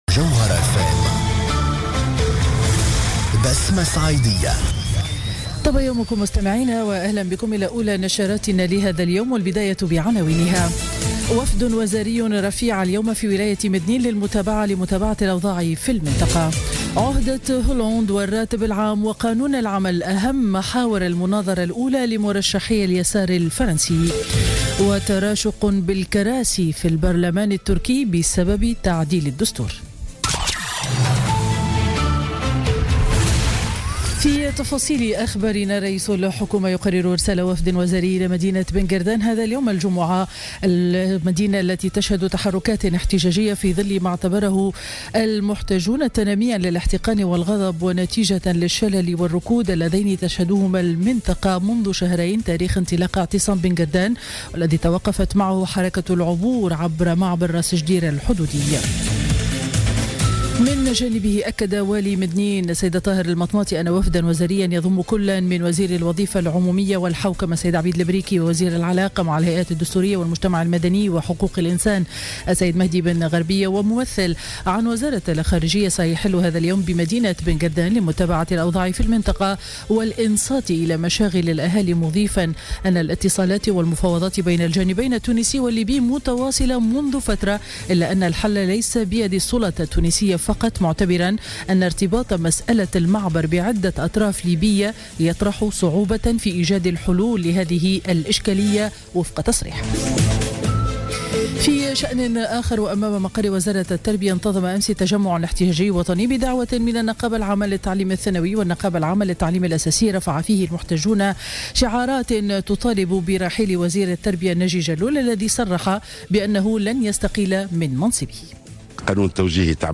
نشرة أخبار السابعة صباحا ليوم الجمعة 13 جانفي 2017